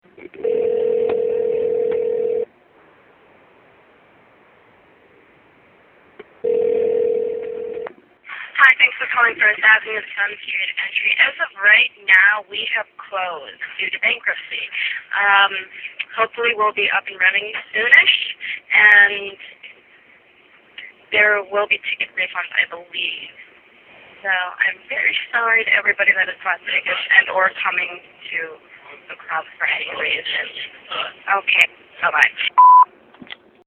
Here's an mp3 of their phone message with details:
First Ave Answering Machine
FirstAveClosingMessage.mp3